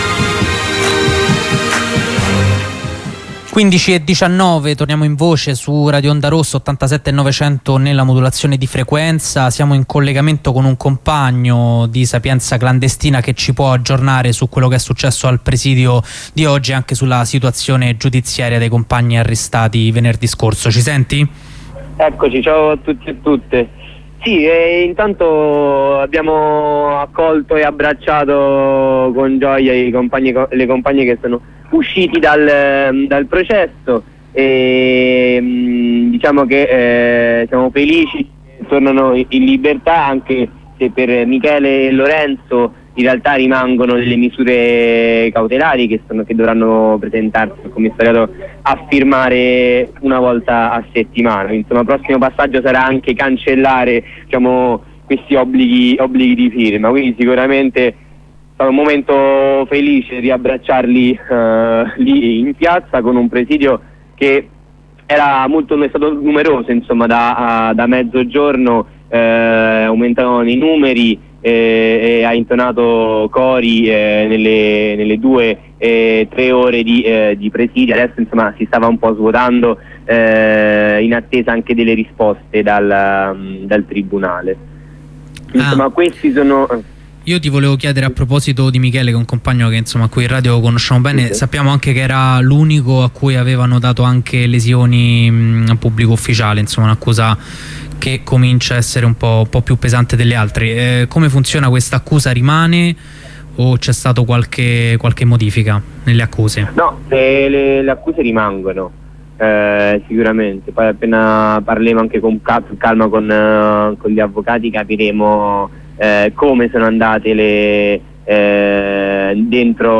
Aggiornamento con un compagno di Sapienza Clandestina sulla situazione giudiziaria dei compagni arrestati venerdì scorso durante l'azione repressiva delle forze dell'ordine alla Maker Faire 2015 che si svolgeva all'interno della Città Universitaria della Sapienza.